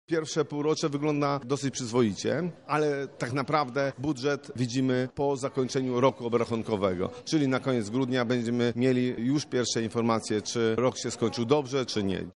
Ocenia je Grzegorz Muszyński, radny sejmiku z PiS.